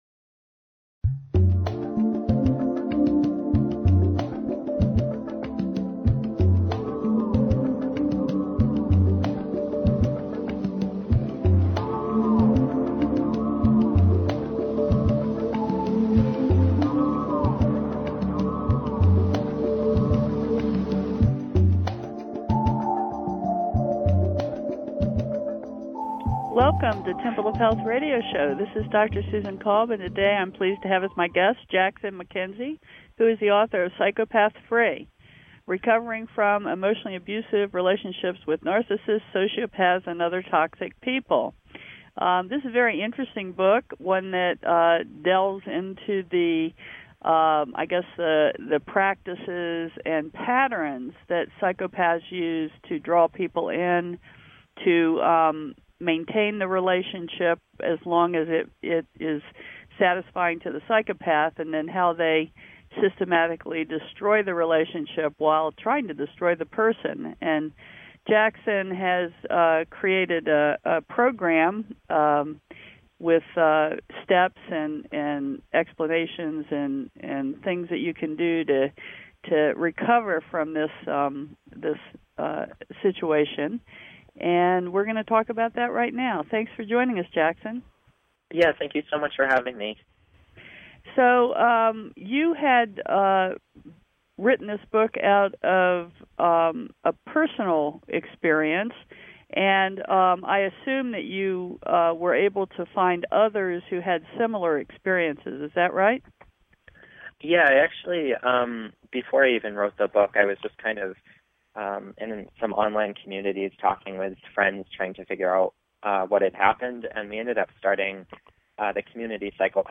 Talk Show Episode
Interview